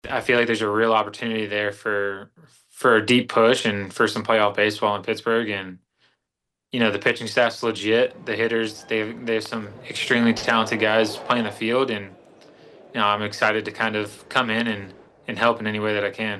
Lowe held a video chat with reporters yesterday and said he is looking forward to splashing a few balls into the Allegheny River.